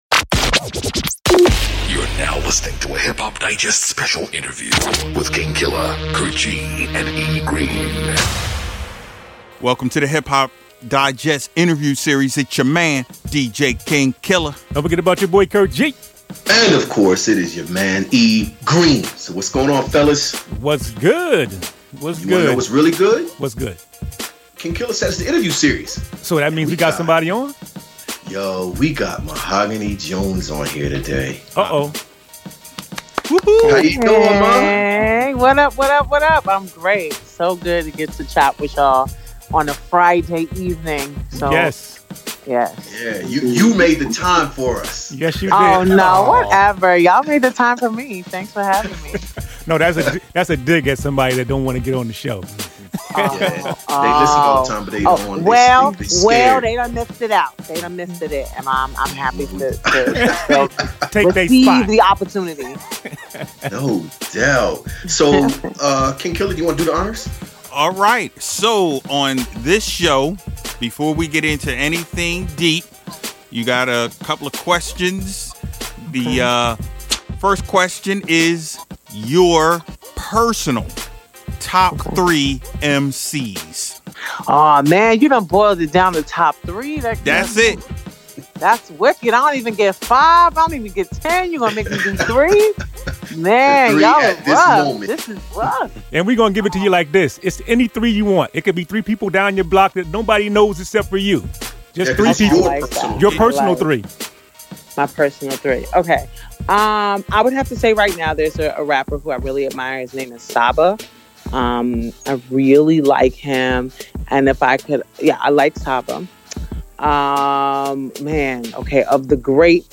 We had the talented MC chat it up with us! This lady is catching wreck out in these Hip Hop streets!